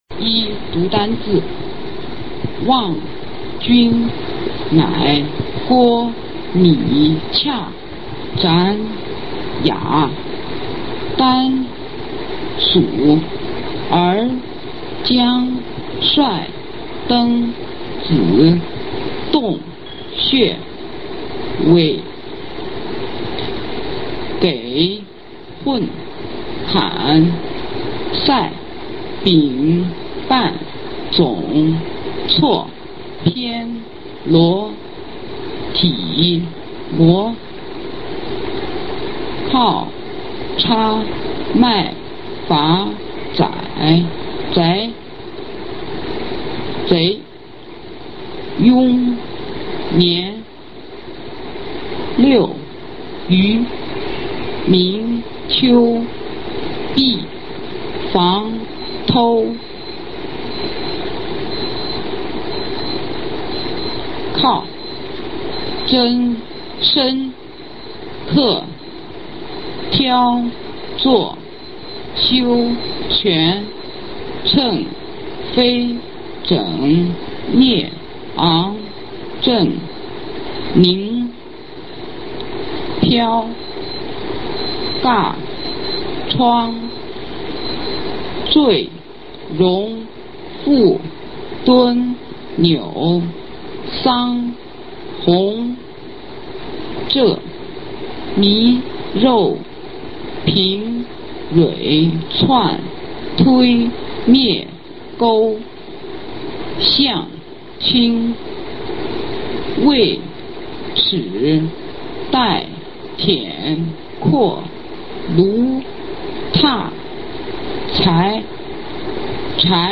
首页 视听 学说普通话 等级示范音频
普通话水平测试三级甲等示范读音
三、朗读
四、说话